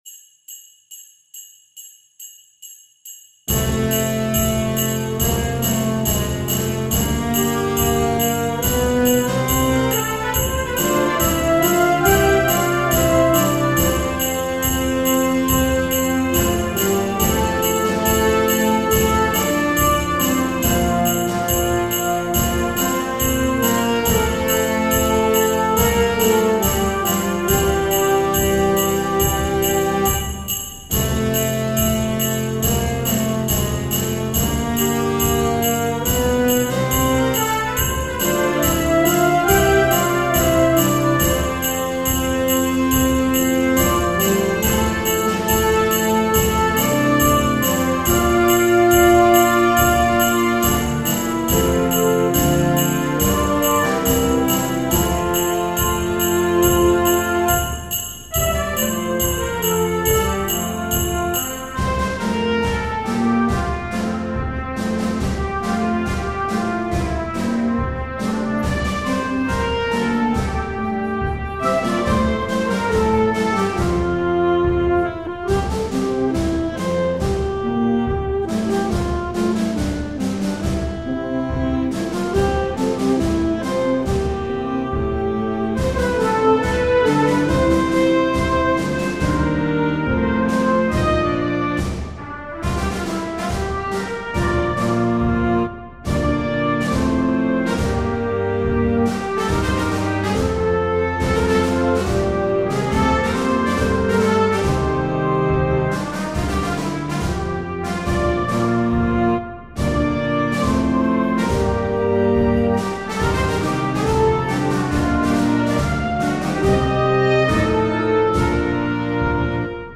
Midi generated.